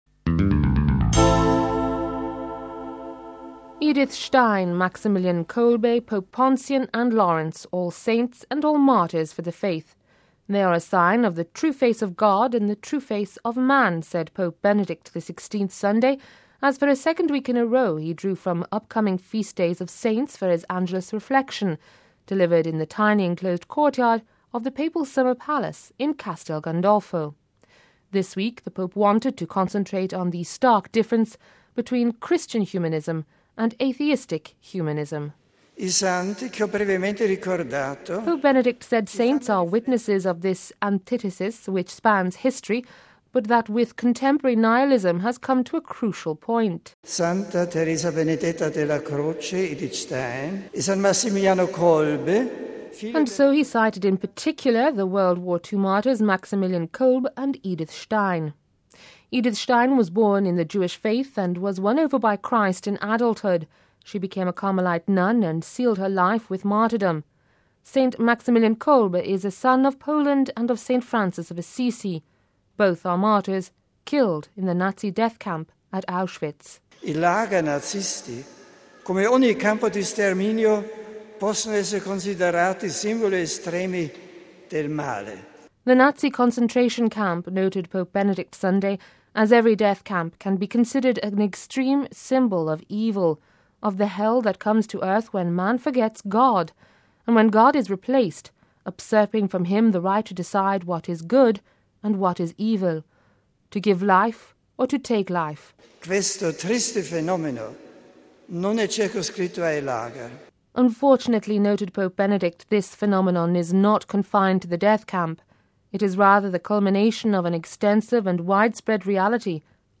They are a sign of the “true face of God and the true face of man” said Pope Benedict XVI Sunday, as for a second week in a row he drew from upcoming feast days of saints for his Angelus reflection, delivered in the tiny enclosed courtyard of the Papal Summer Palace in Castel Gandolfo.